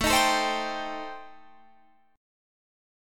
Listen to Am7b5 strummed